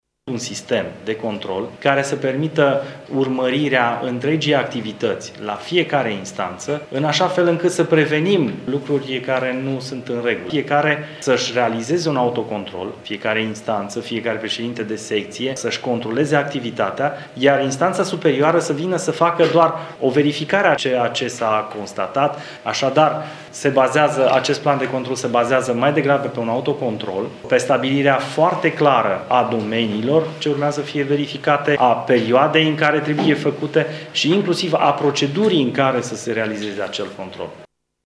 Anunţul a fost făcut cu ocazia prezentării bilanţului instanţei.
Florin Dima a spus că instanţa mureşeană este singura din ţară care a implementat un astfel de sistem: